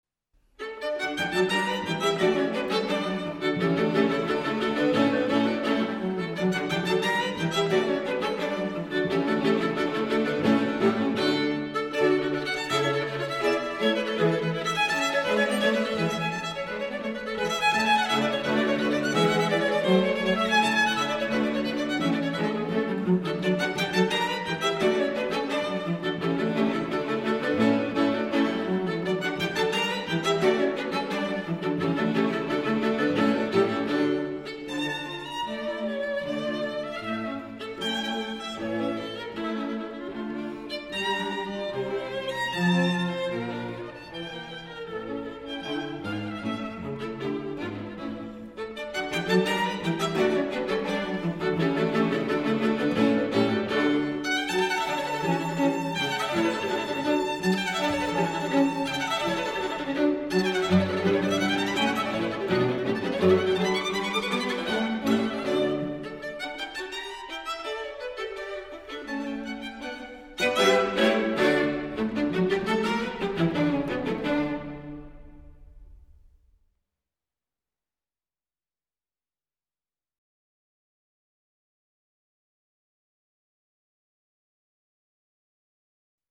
String Quartet in D major
Molto Allegro